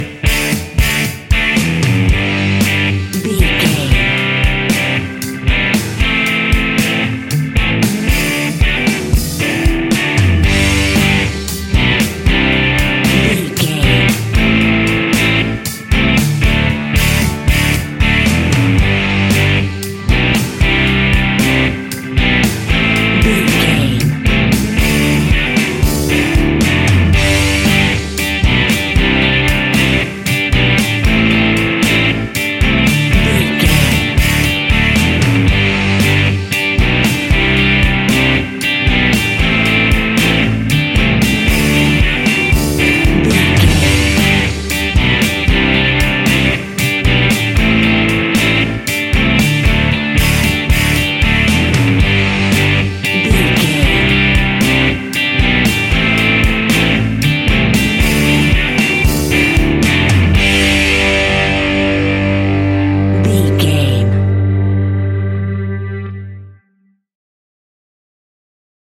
Ionian/Major
fun
energetic
uplifting
acoustic guitars
drums
bass guitar
electric guitar
piano
organ